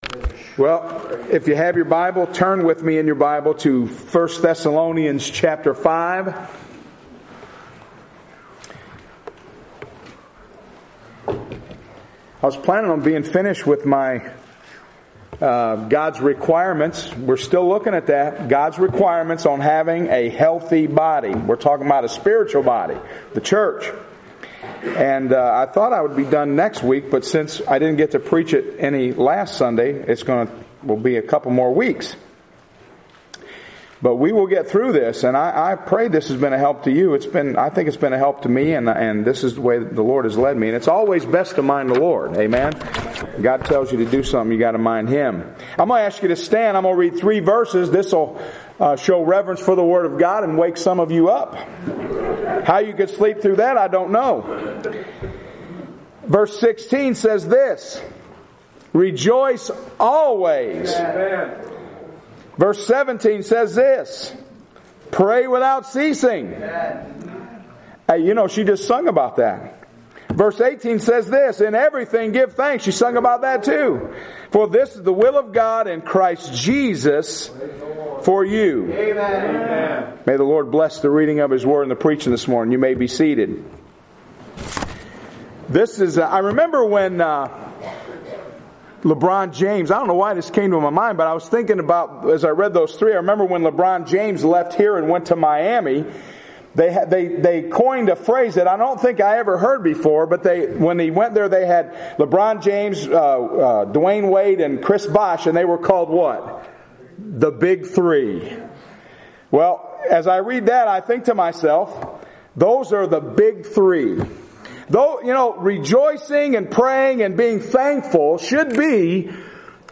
Sermon Audio — Unity Free Will Baptist Church